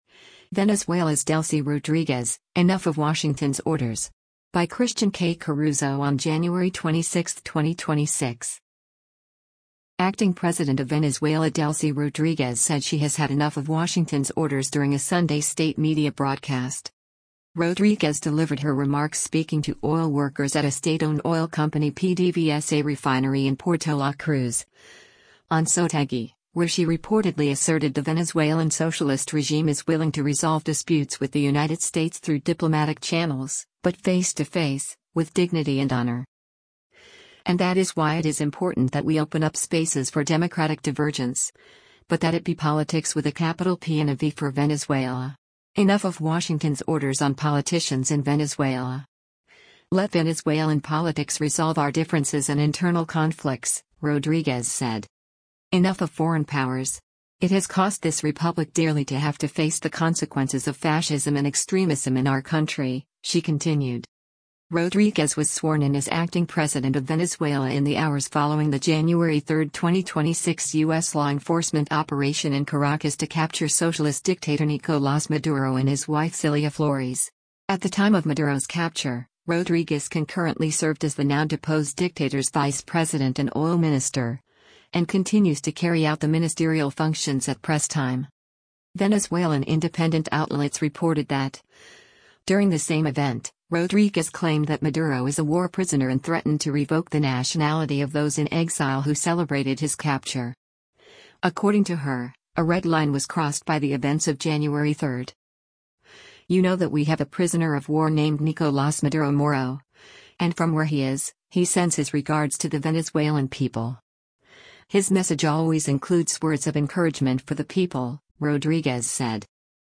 “Acting President” of Venezuela Delcy Rodríguez said she has had “enough” of Washington’s orders during a Sunday state media broadcast.
Rodríguez delivered her remarks speaking to oil workers at a state-owned oil company PDVSA refinery in Puerto La Cruz, Anzoátegui, where she reportedly asserted the Venezuelan socialist regime is willing to resolve disputes with the United States through diplomatic channels, but “face to face,” with “dignity and honor.”